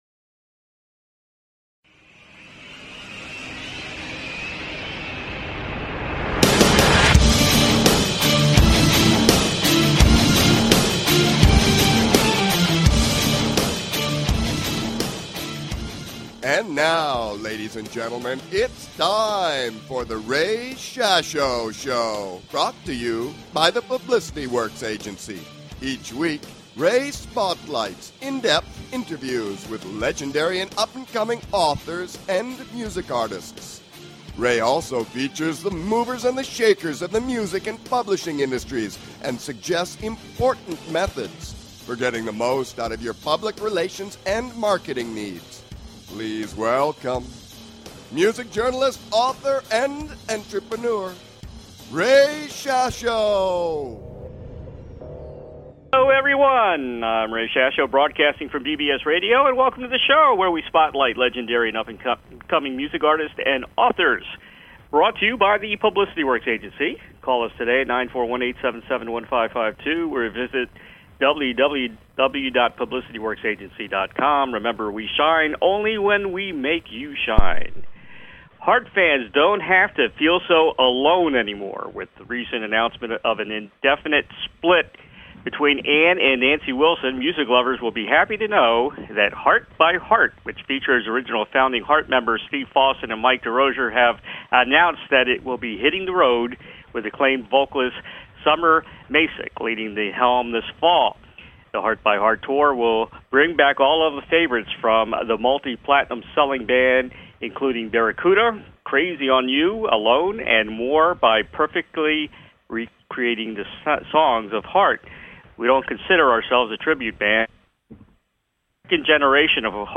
Guest, Steve Fossen and Mike Derosier